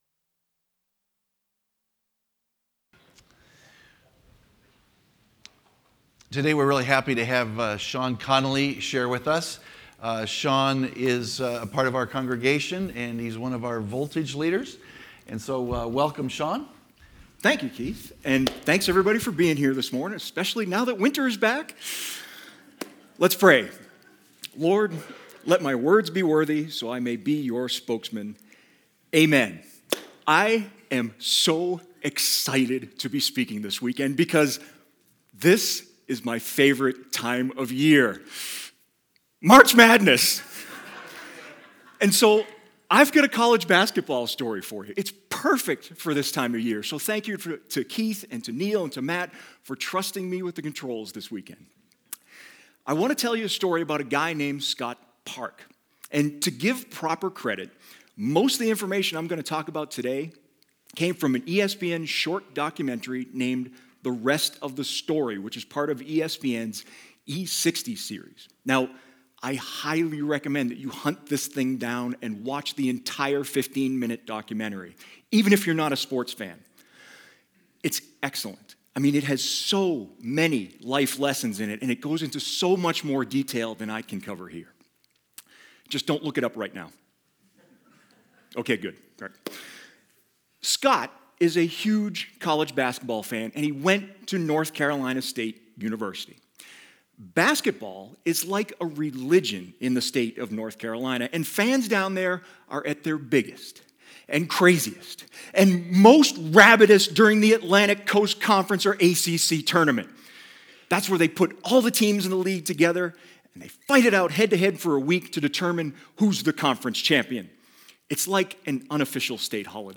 For over a year, the basis of a sermon rattled around in my head. And finally this past weekend, I got the chance to present one of those life lessons at Life Covenant Church in Helena.